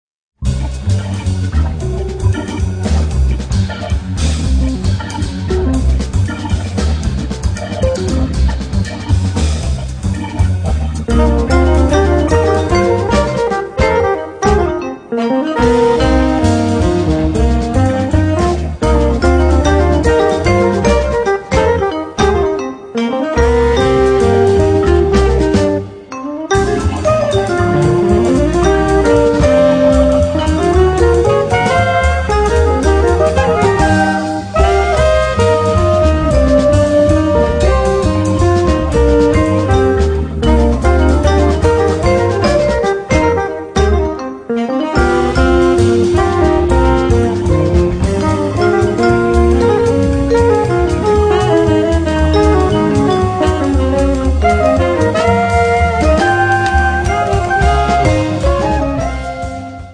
batteria
flauto e sax soprano
pianoforte
sax tenore